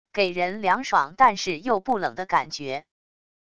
给人凉爽但是又不冷的感觉wav音频